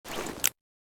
3098b9f051 Divergent / mods / Boomsticks and Sharpsticks / gamedata / sounds / weapons / aps / aps_draw.ogg 21 KiB (Stored with Git LFS) Raw History
aps_draw.ogg